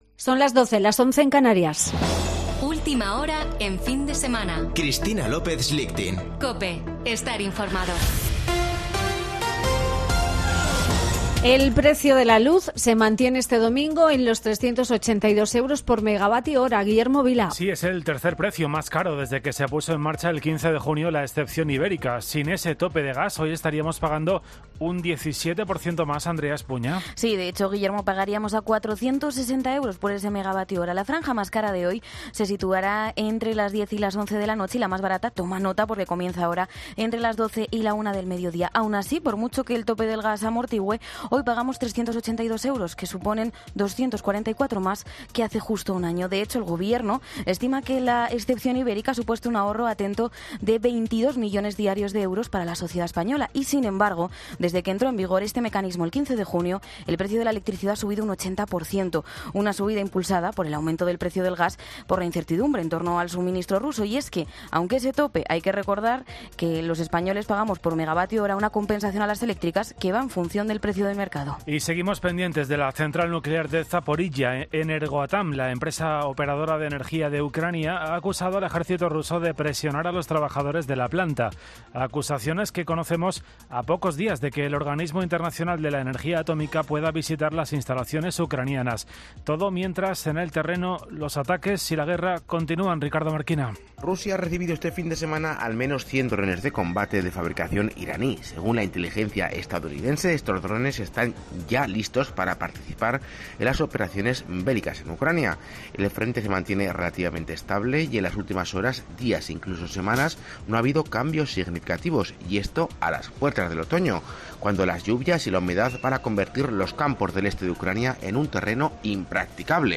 Boletín de noticias de COPE del 28 de agosto de 2022 a las 12.00 horas